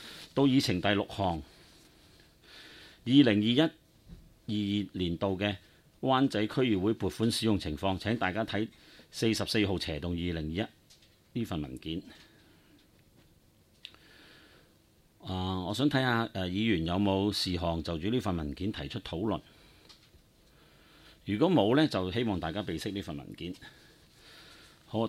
区议会大会的录音记录
湾仔区议会第十四次会议
湾仔民政事务处区议会会议室